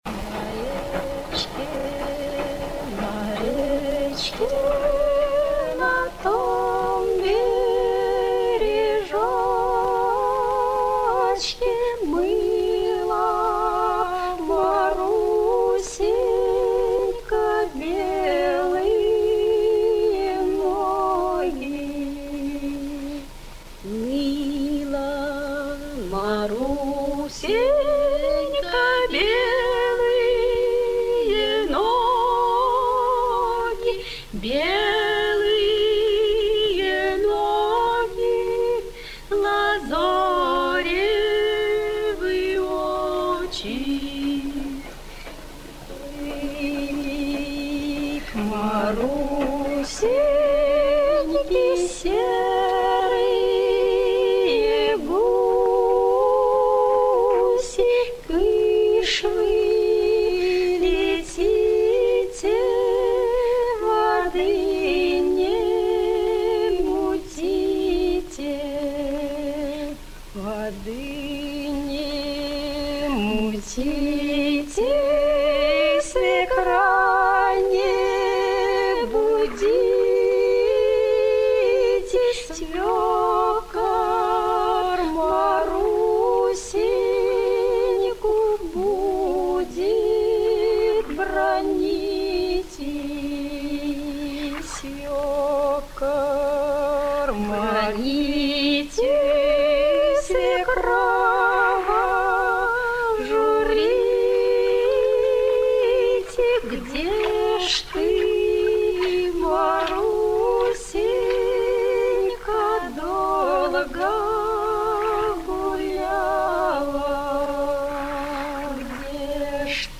Саундтрек из фильма Вступление